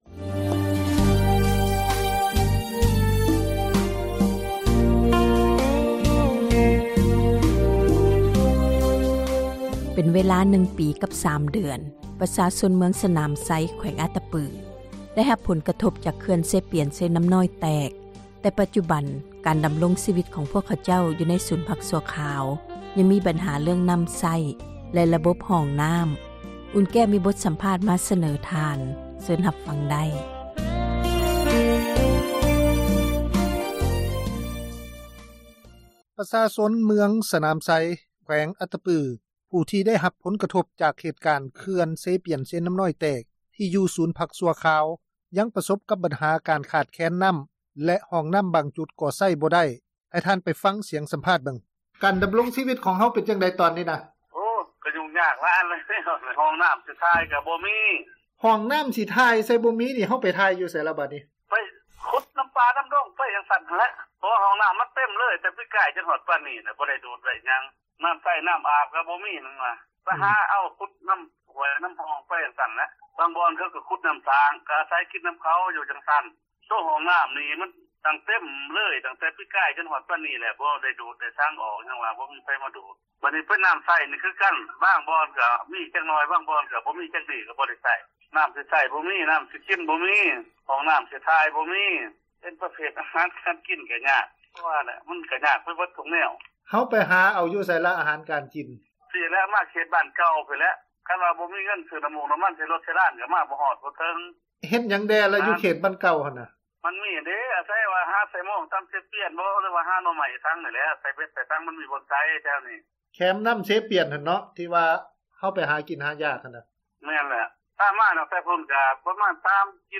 ມີບົດສັມພາດ ມາສເນີ ທ່ານ.